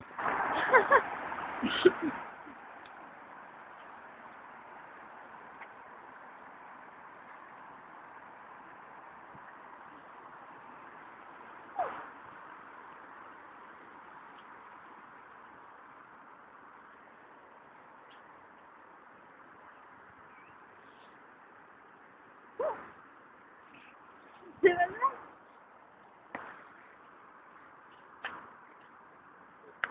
Voitures et petits cris